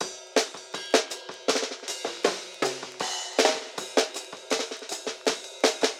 The effects are in line and we achieved a nice old 30′s like vinyl/radio sound.